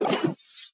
Cybernetic Technology Affirmation 3.wav